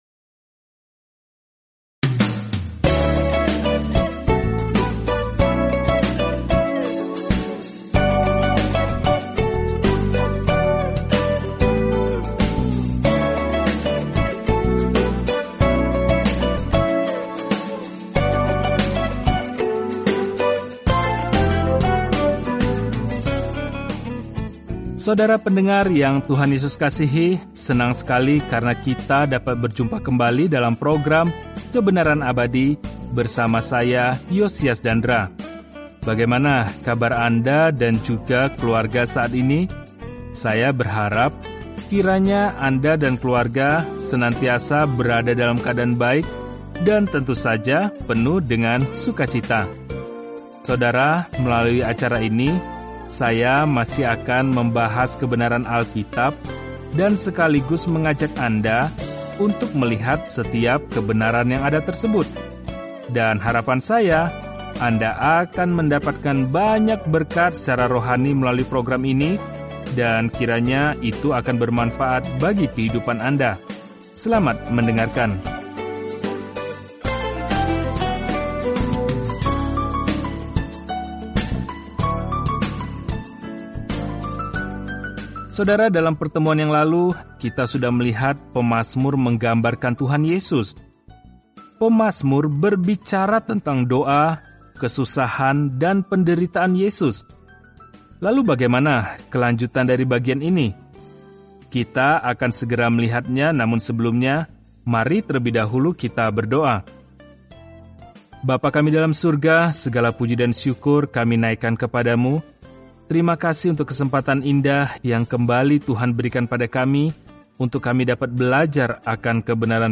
Firman Tuhan, Alkitab Mazmur 103 Mazmur 104 Mazmur 105 Mazmur 106 Hari 41 Mulai Rencana ini Hari 43 Tentang Rencana ini Mazmur memberi kita pemikiran dan perasaan tentang serangkaian pengalaman bersama Tuhan; kemungkinan masing-masing aslinya disetel ke musik. Bacalah Mazmur setiap hari sambil mendengarkan pelajaran audio dan membaca ayat-ayat tertentu dari firman Tuhan.